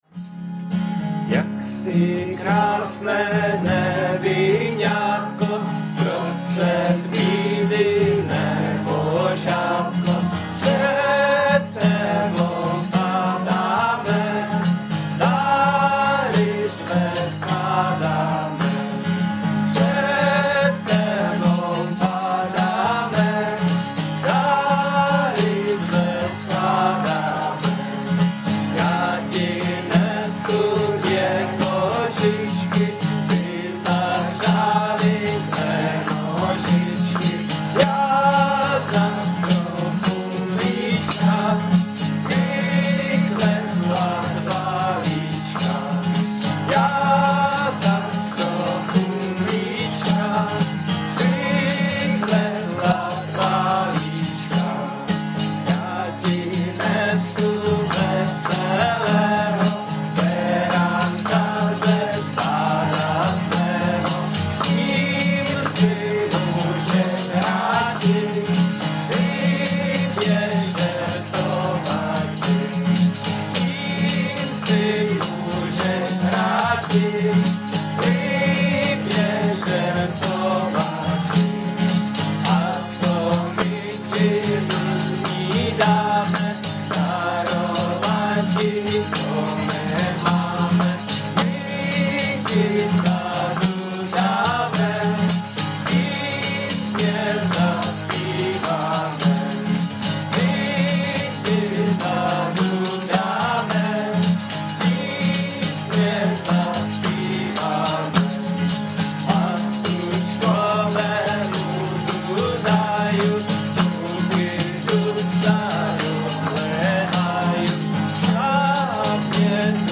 nahrávka ze zpívání